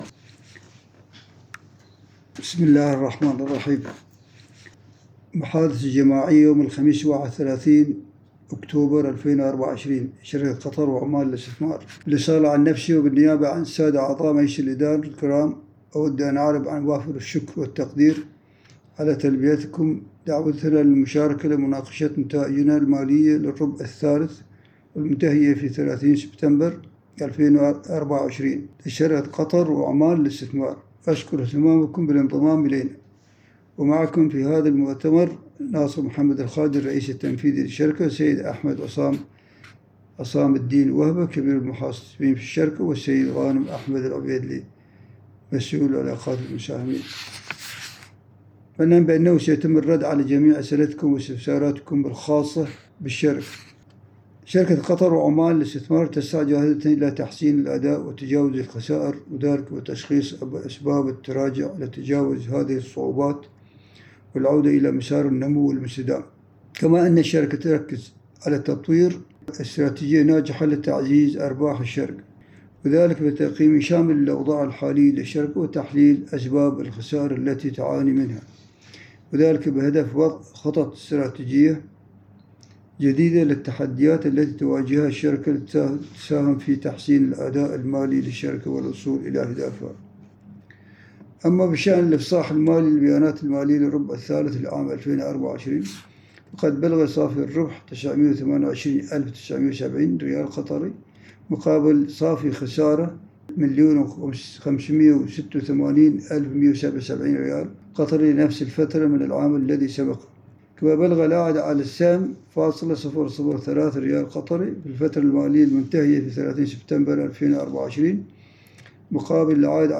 المكالمة الجماعية